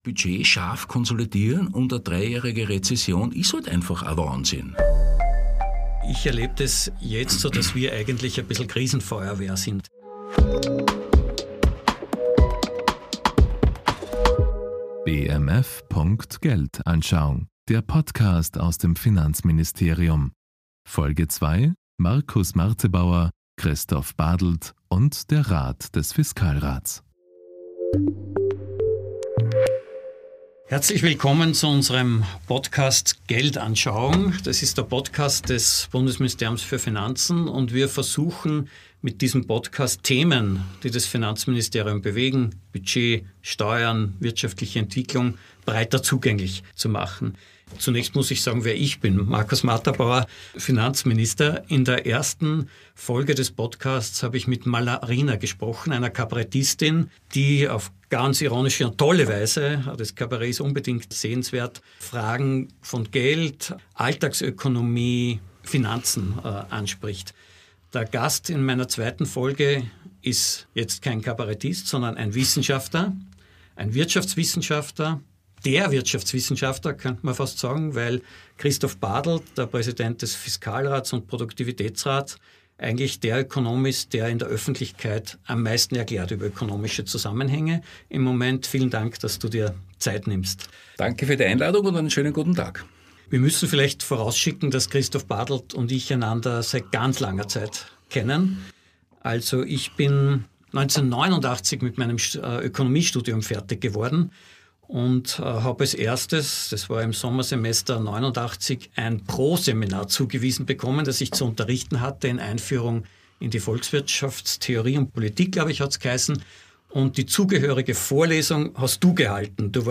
Der Präsident des Fiskalrats und Vorsitzender des Produktivitätsrats Christoph Badelt und Finanzminister Markus Marterbauer sprechen über Ökonomie, Budgetsanierung, Strukturwandel und wie der Wohlstand gesichert werden kann.